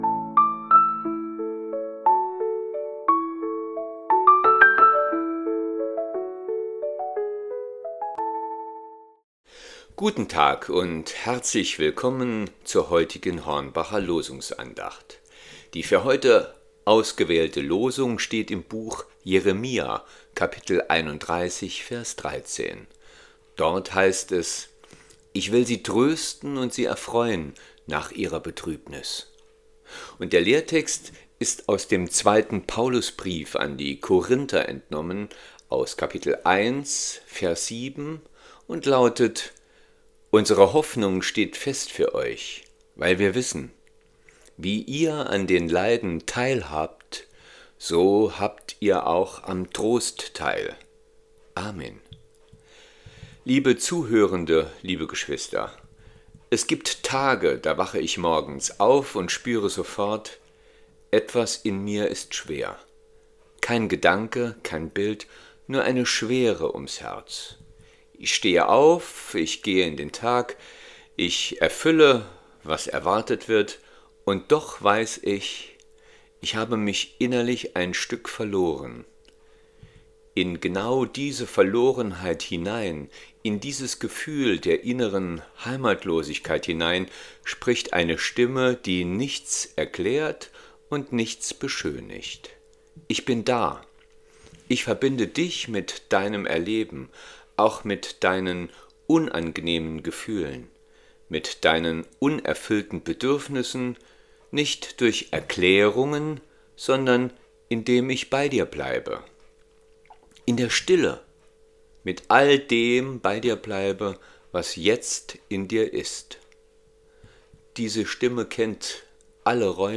Losungsandacht für Freitag, 09.01.2026